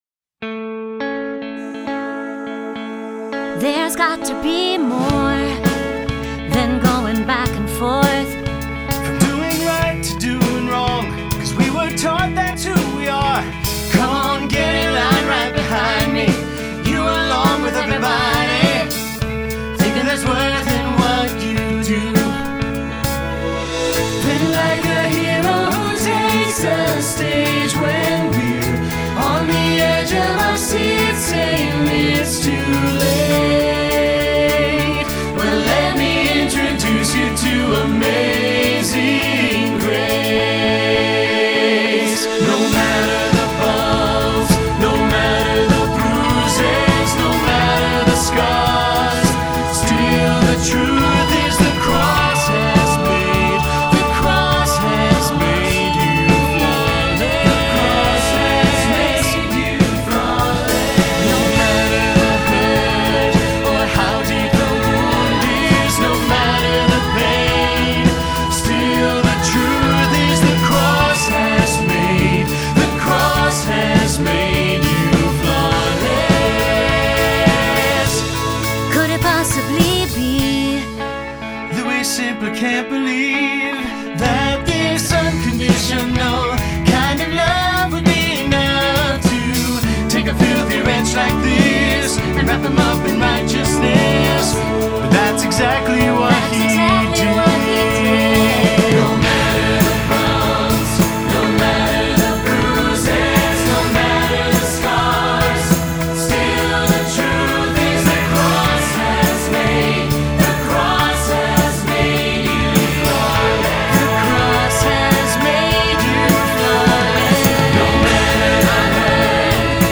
Choral Church